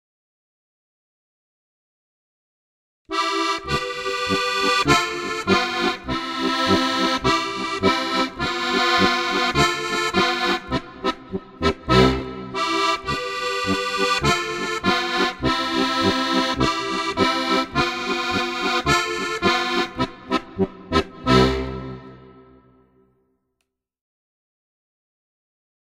• Für 3- und 4-reihige Harmonika